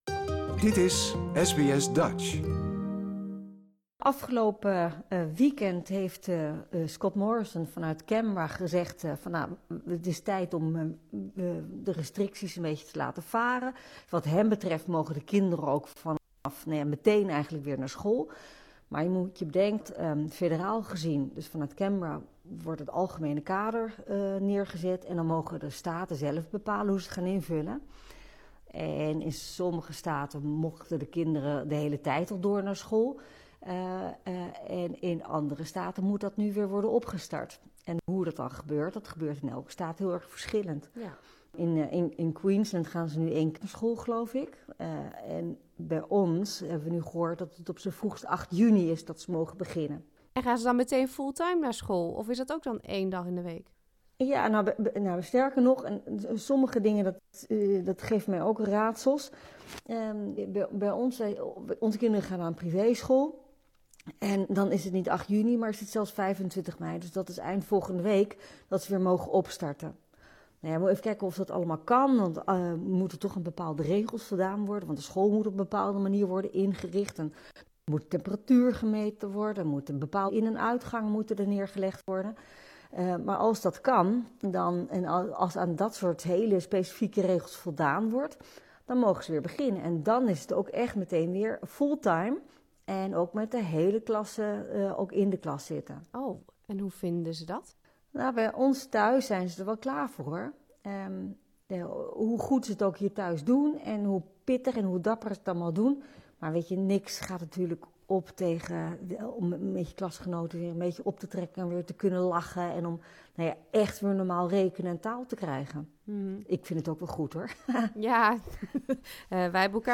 Zeker nu Australië en China op een handelsoorlog lijken af te stevenen. We bespreken deze en andere actuele politieke zaken met onze vaste politiek deskundige en voormalig Tweede Kamerlid Nicolien van Vroonhoven.